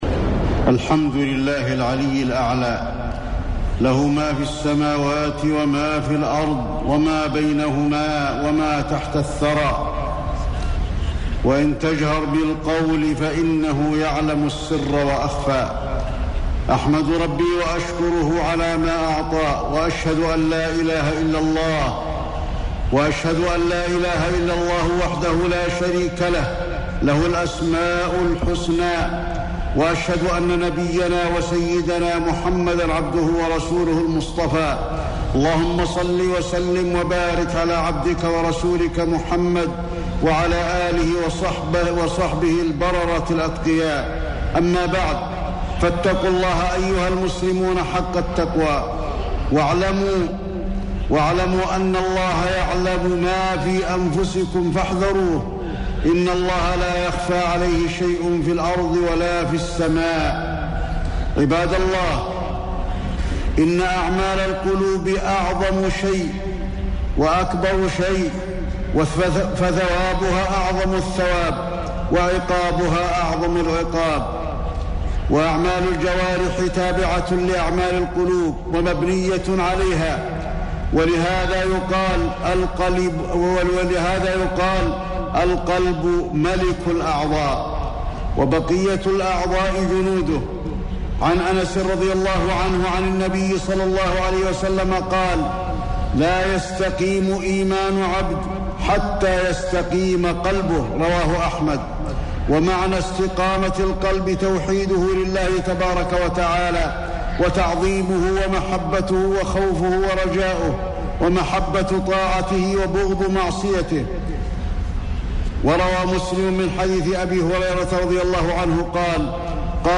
خطبة الجمعة 1-7-1432 هـ | موقع المسلم
خطب الحرم المكي